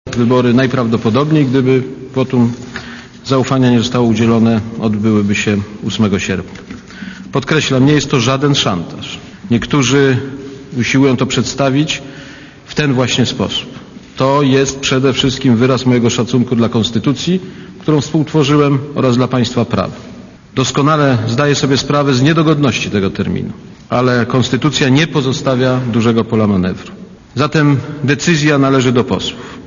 Posłuchaj wypowiedzi prezydenta